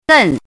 怎么读
gèn
gen4.mp3